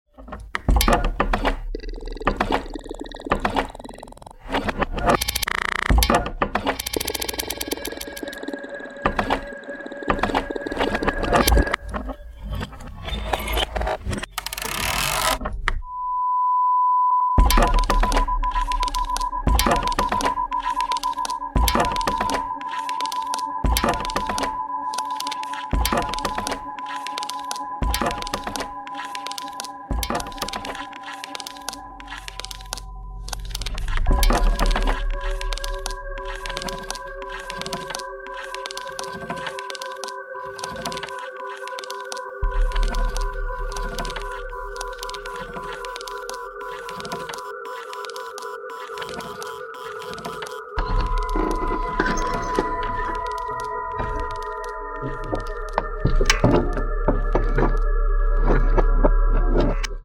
Musique Concréte
analogue synthesizer
percussion, electronics
guitar, objectophones
toy instruments
Niniejszy album zawiera nagranie tego wyjątkowego koncertu.